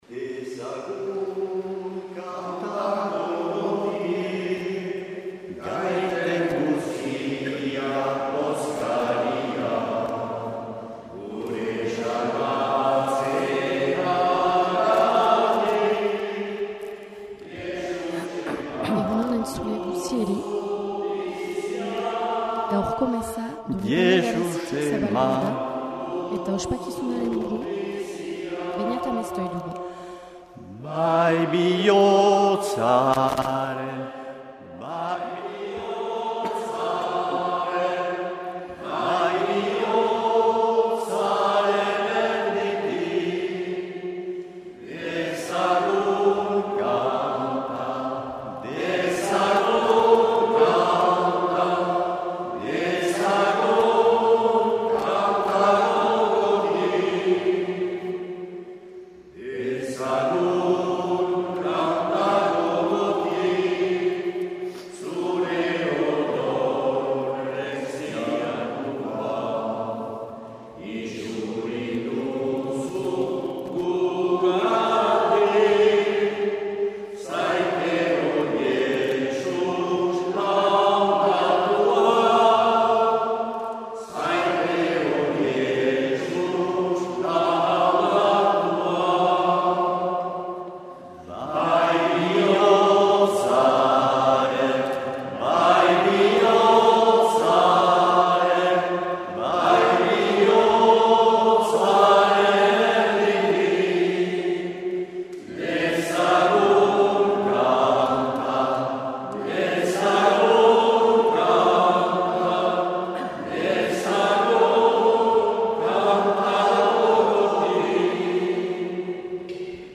Accueil \ Emissions \ Vie de l’Eglise \ Célébrer \ Igandetako Mezak Euskal irratietan \ 2023-10-15 Urteko 28.